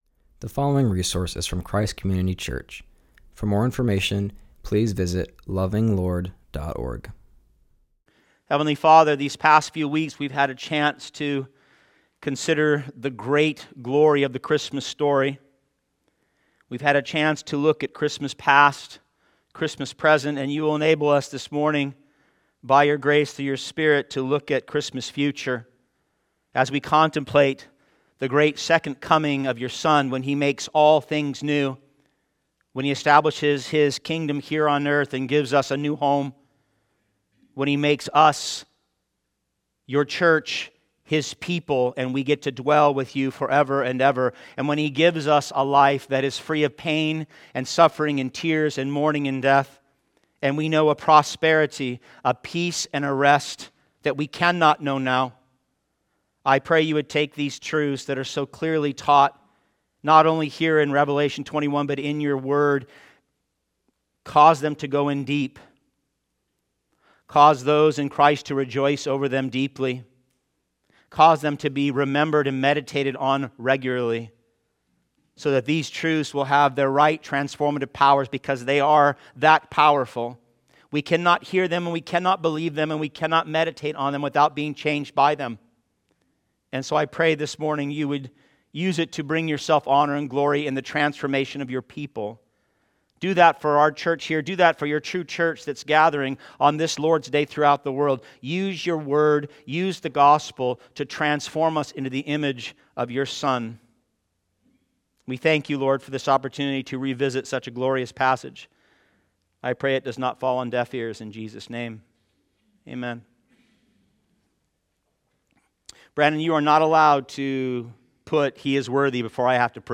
preaches on Christmas Future.